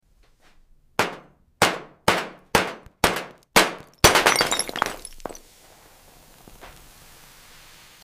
A POV shot of a rough, dark meteorite-like sphere on a brushed steel table. A hammer strike creates a deep, satisfying “thud” followed by a sharp “crack”. The broken pieces clink lightly against the table with soft metallic overtones. As molten caramel oozes out, a slow, sticky “sluuuurp” is heard, accompanied by faint sizzling steam.